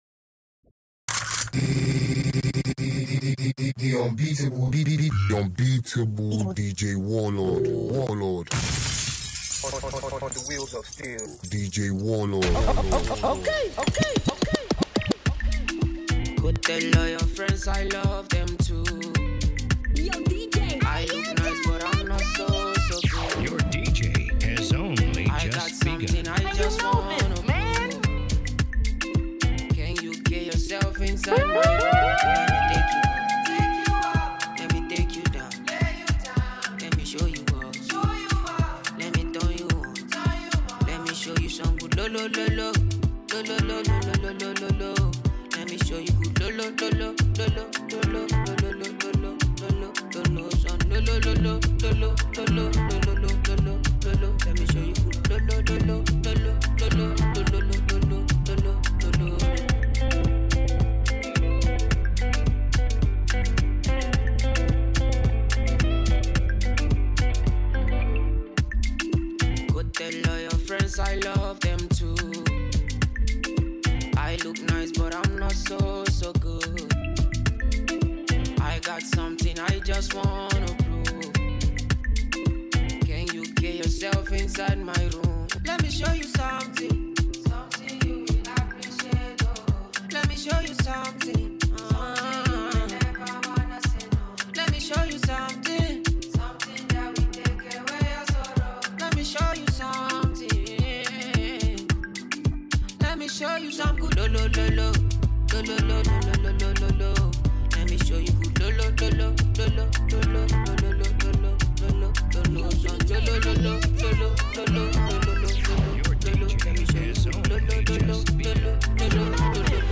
party mix
DJ Mix